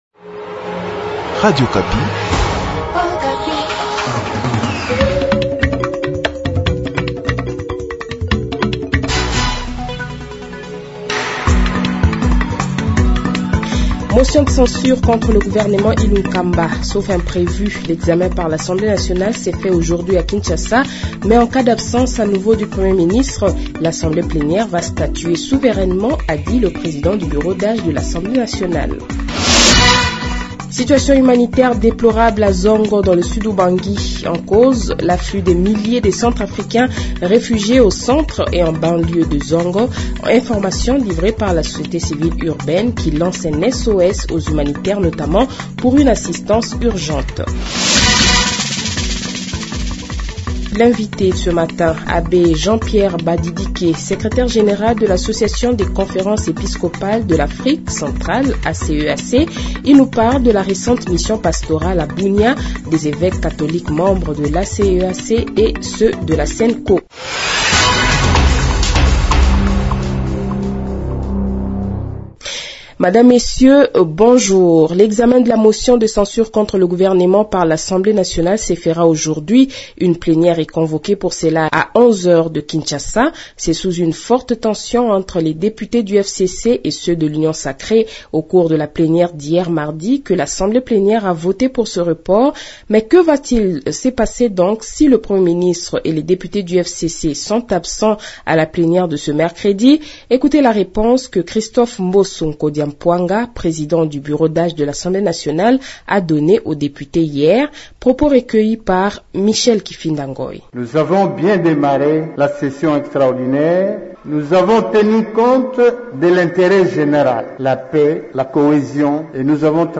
JournalFrancaisMatin